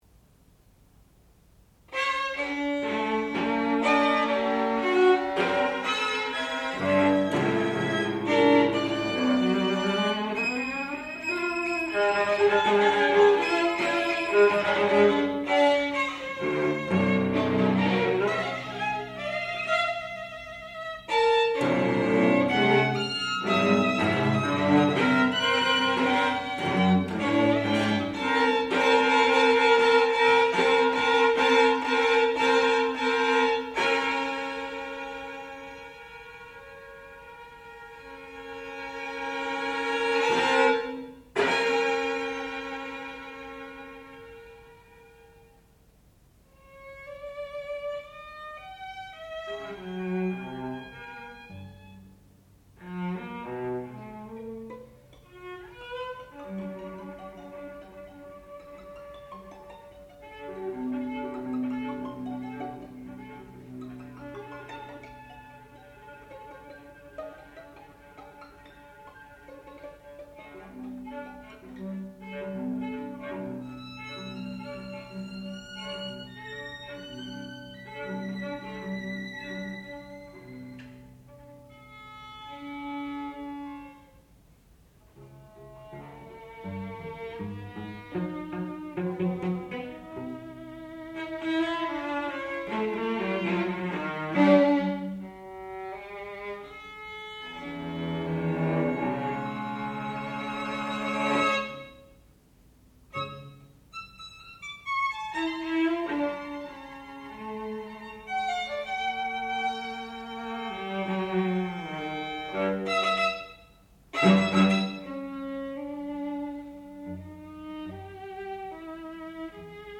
sound recording-musical
classical music
violin
violoncello